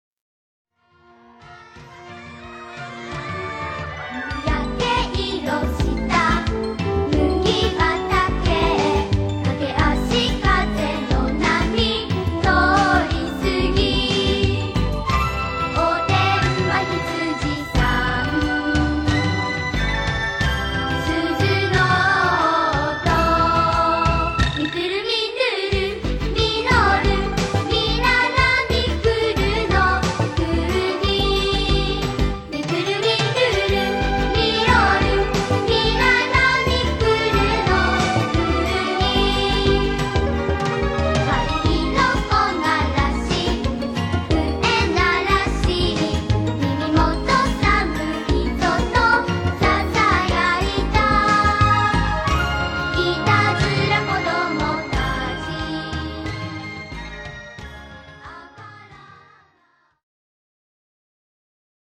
簡単なアニメ調の曲でアコギをレコーディングしたんやけど、結構緊張しましたなあ(^^;)。
でも完成を聴いてちょっとショック、ほとんどギターは聴こえてこない(^o^;)(